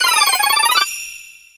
Cries
CHINGLING.ogg